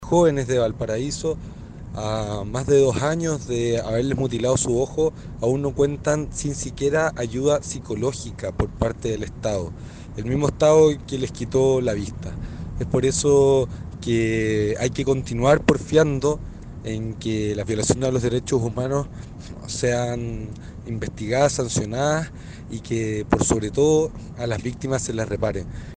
En tanto el diputado Jorge Brito (RD), manifestó que es necesario reparar la dignidad de los afectados y criticó al Estado, ya que ni siquiera entregó ayuda psicológica.